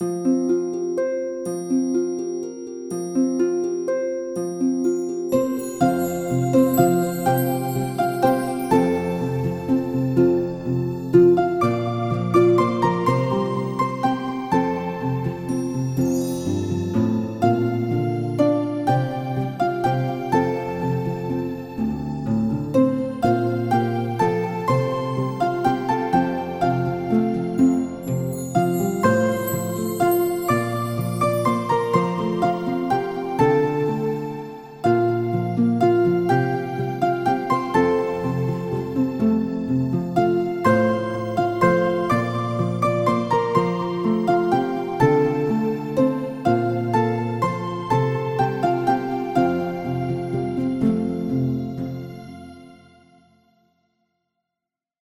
【１. 地圖背景音樂】